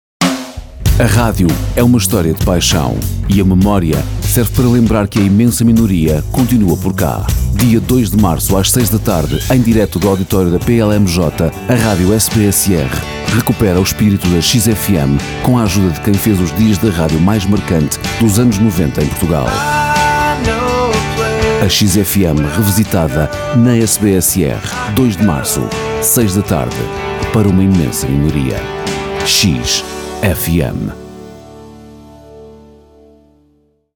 Ouça o teaser
Promo_XFM.mp3